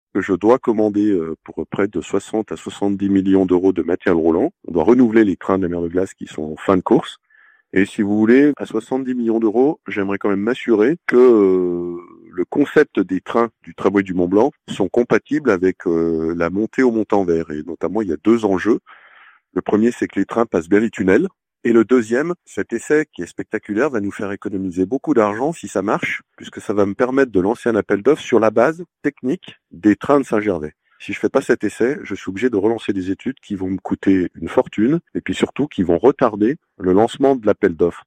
Cette opération, chiffrée à 120 000 euros, vise à préparer le renouvellement du matériel roulant du Montenvers. Il s'agit en effet d'un test technique, comme l'explique Martial Saddier.